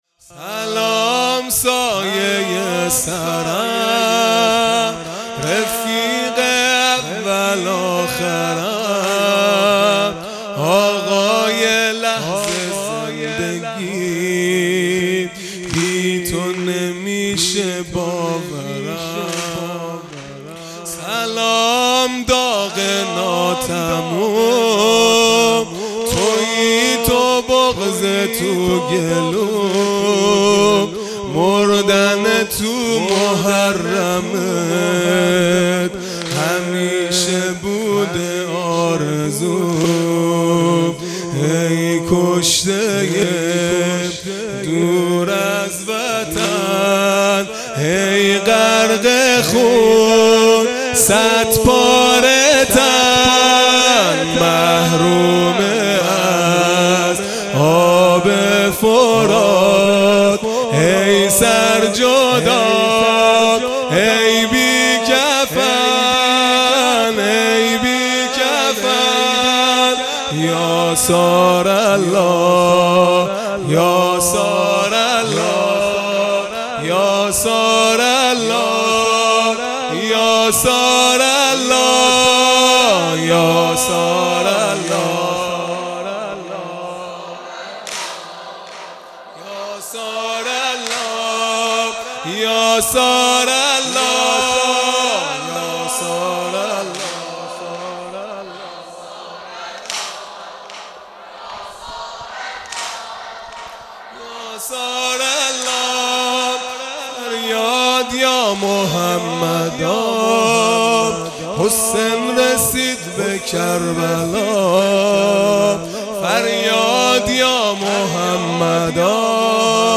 خیمه گاه - هیئت بچه های فاطمه (س) - واحد | سلام سایۀ سرم | سه شنبه ۱۹ مرداد ۱۴۰۰